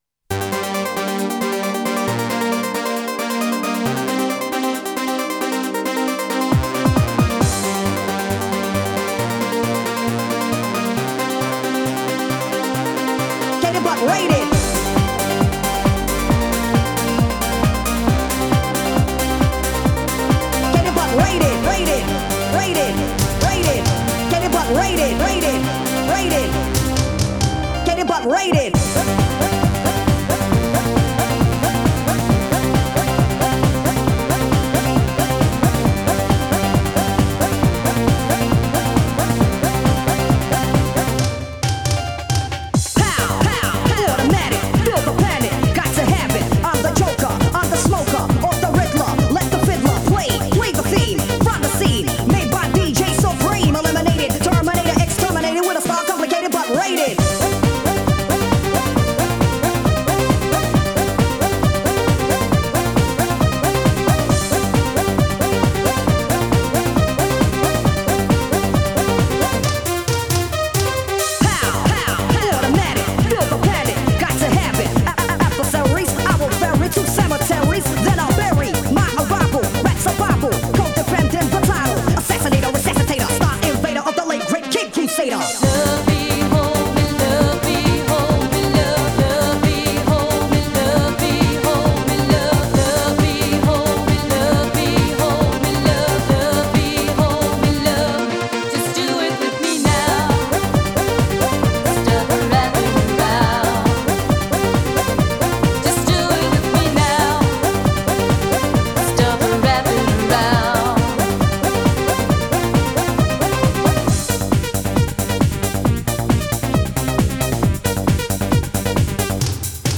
Genre: Italodance.